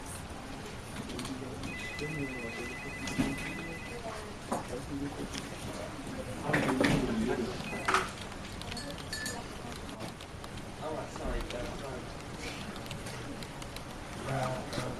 Helicopter
Helicopter is a free ambient sound effect available for download in MP3 format.
359_helicopter.mp3